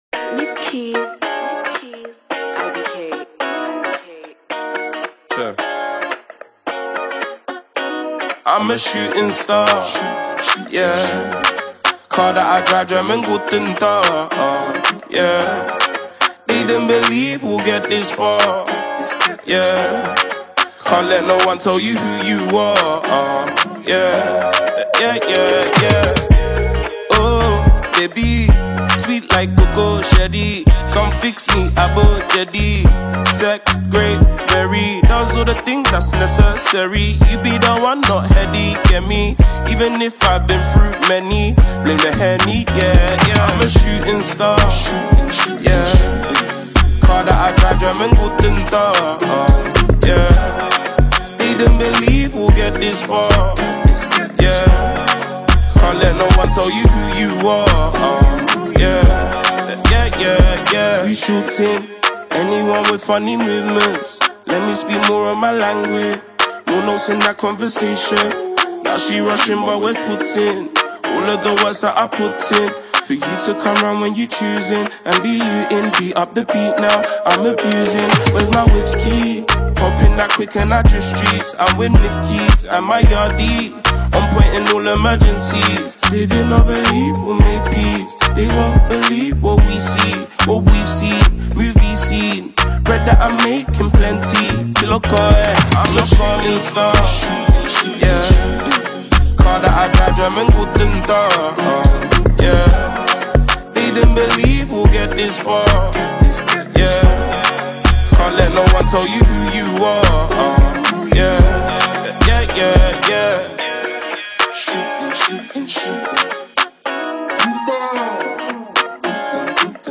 Nigerian singer-songwriter